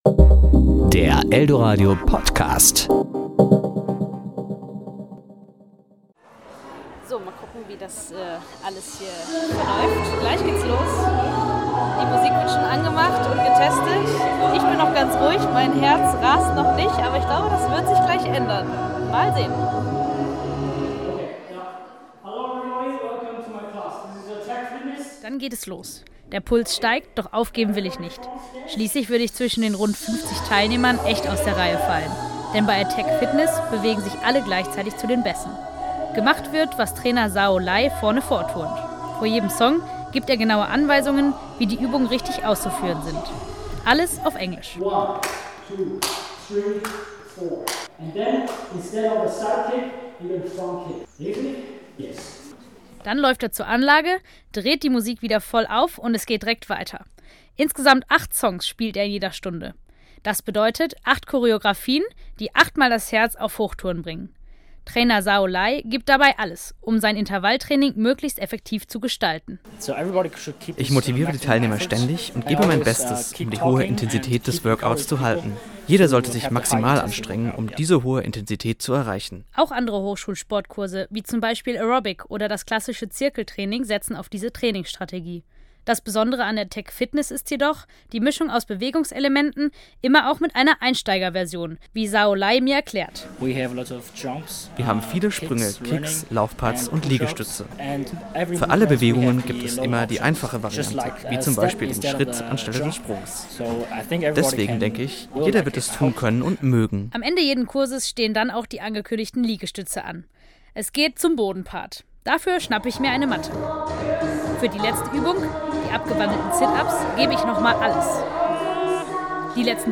Beiträge  Ressort: Wort  Sendung